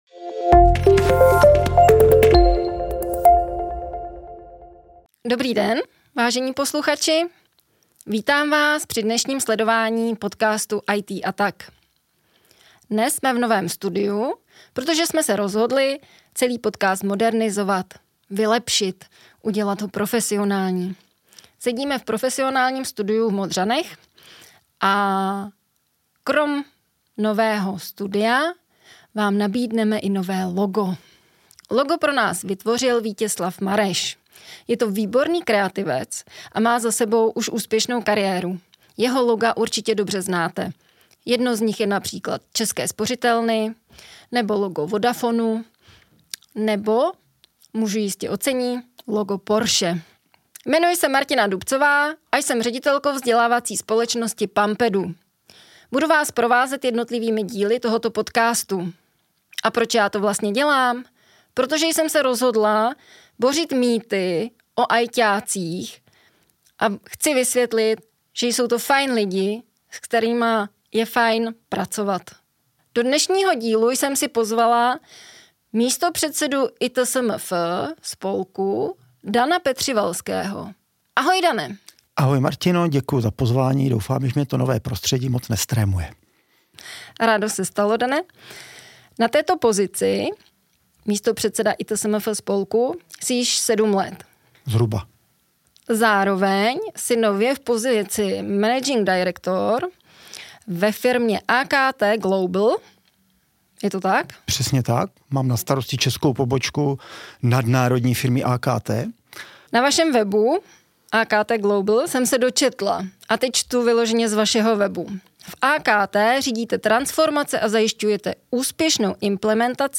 V dnešním rozhovoru nahlédneme pod pokličku IT Service Management (ITSM) a mezinárodního spolku itSMF (ITSM Forum), který spojuje profesionály v tomto segmentu.